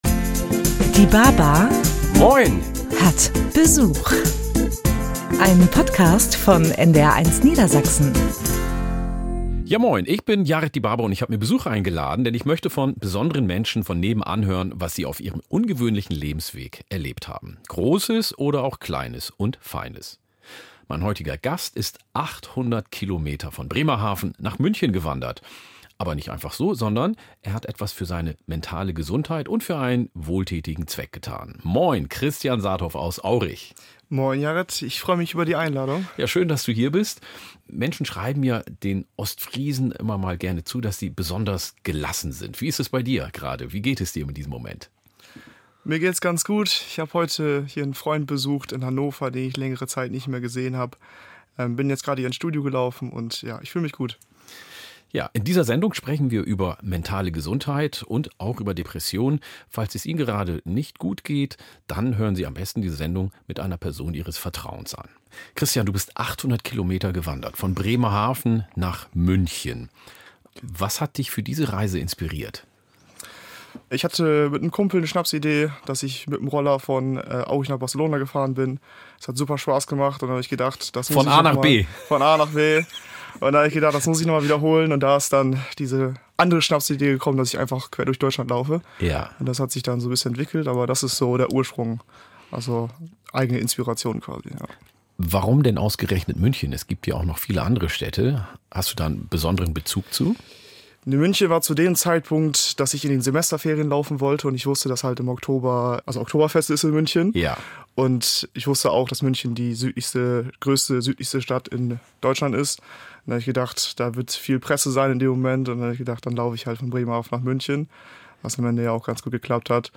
Jeden ersten Samstag im Monat lädt er spannende Menschen von nebenan ein und plaudert mit ihnen über Gott und die Welt – und über das, was sie ausmacht.